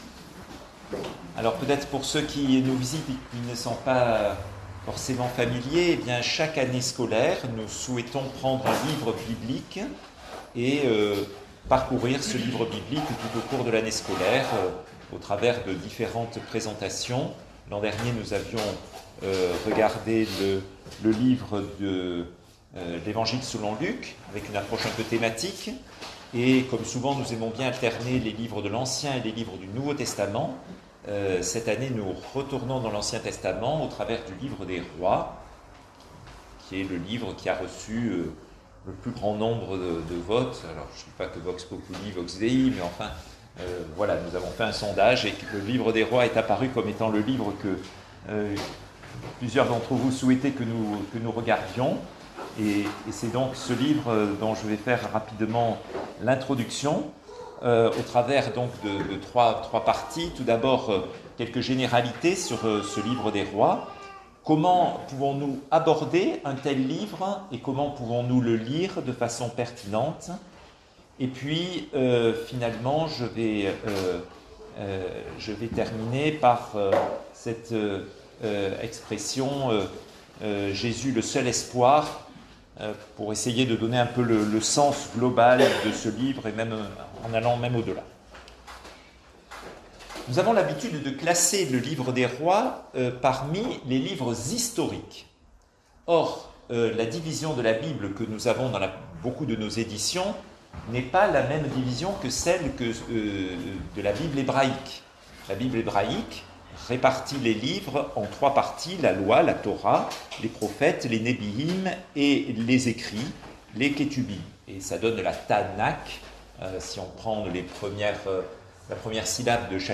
Thème(s) : Etude sur le livre des Rois